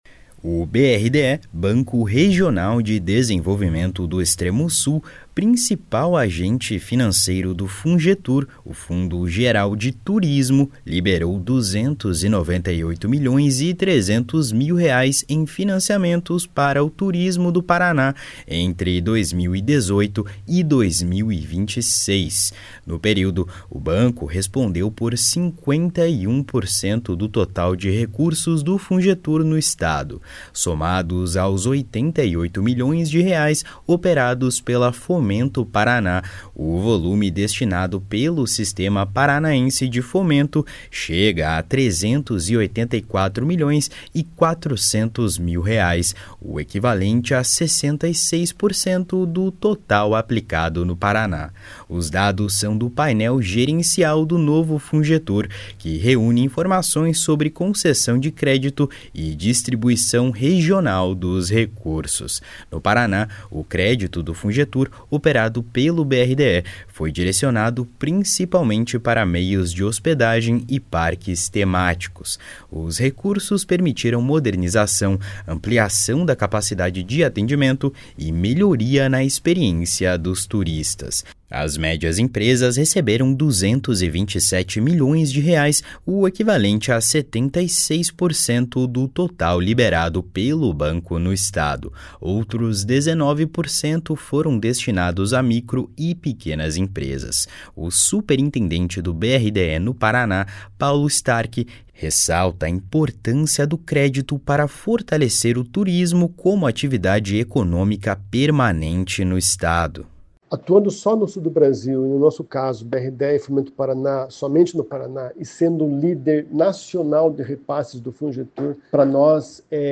PARANA FORTALECE TURISMO COM R$ 300 MILHOES.mp3